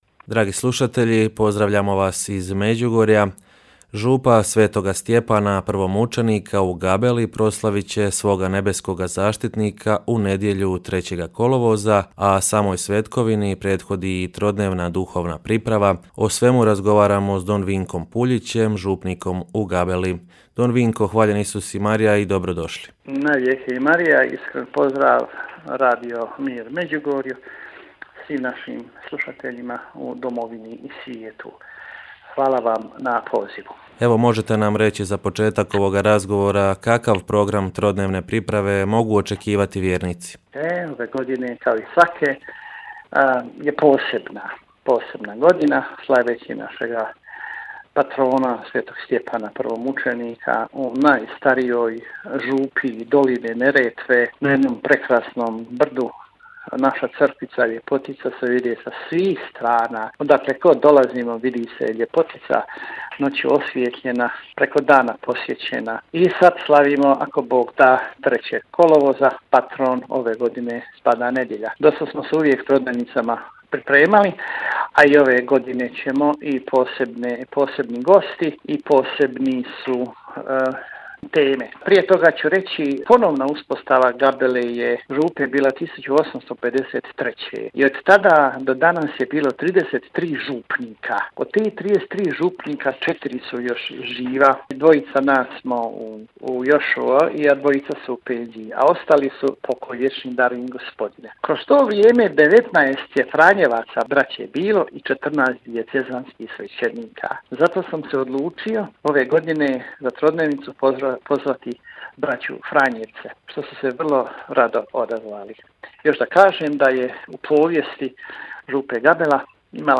Župa Svetoga Stjepana Prvomučenika u Gabeli, proslavit će svoga nebeskoga zaštitnika u nedjelju 3. kolovoza, gost u našem programu